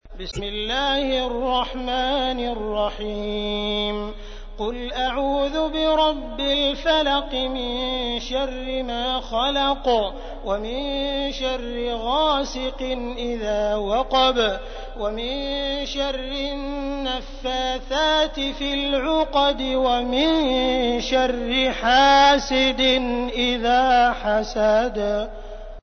تحميل : 113. سورة الفلق / القارئ عبد الرحمن السديس / القرآن الكريم / موقع يا حسين